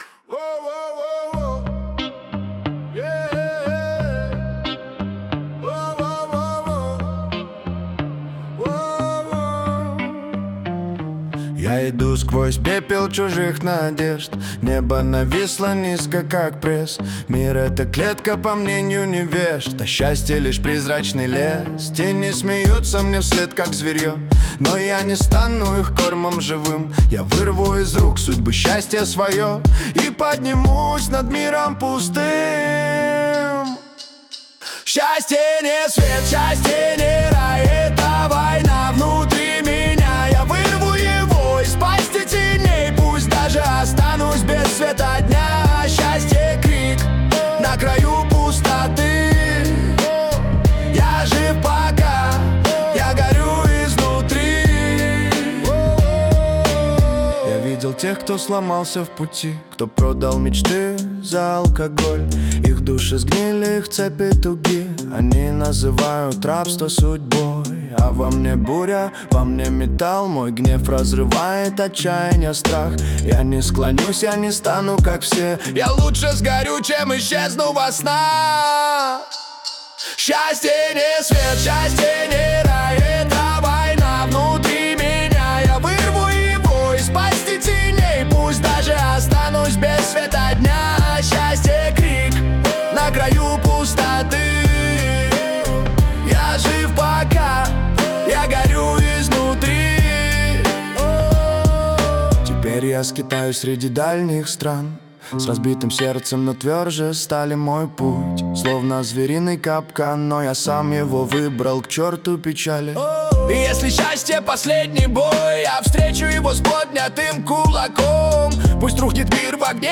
хип-хоп, медленный темп, чёткий бит, аналоговый синтезатор, синтезаторные аккорды в припевах, соло на синтезаторе в аутро
• уточнено место синтезатора (припевы, аутро);
• задано поведение звука (аккорды и соло).